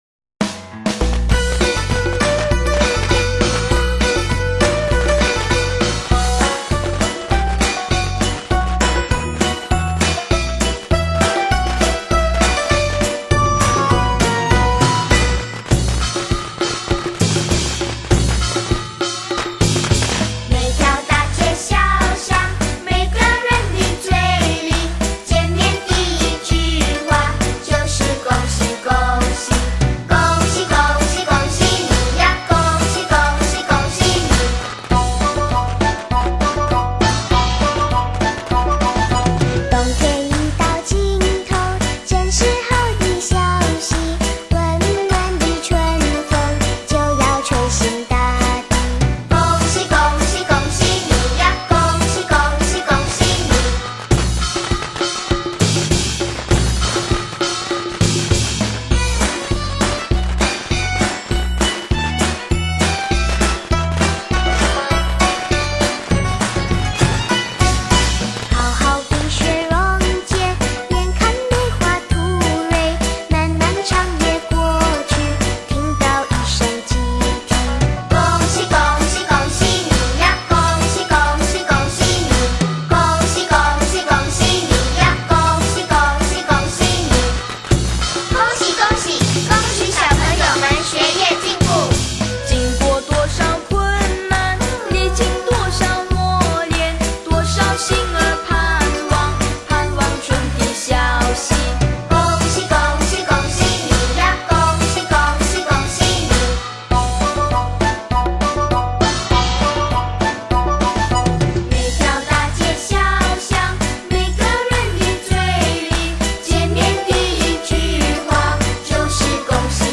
Chinese New Year Celebration Song
Solo Recorder